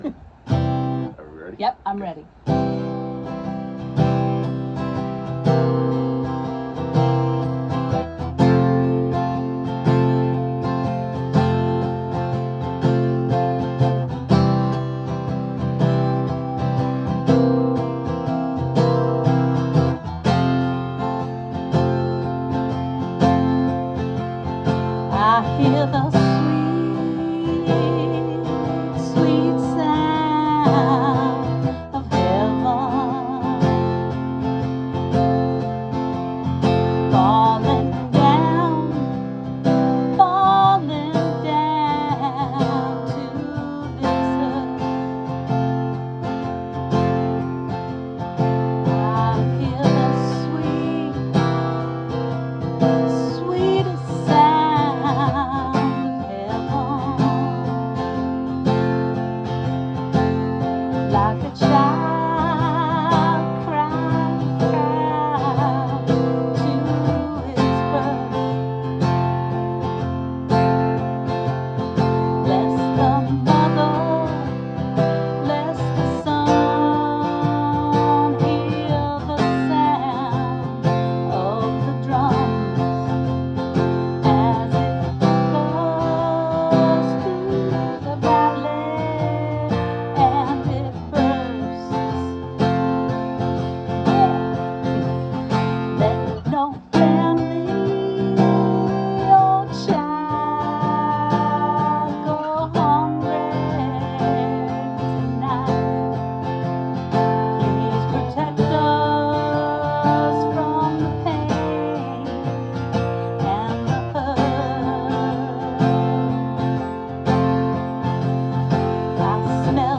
Mother's Day 2024
(WORDS/STRUCTURE| Rehearsal VIDEO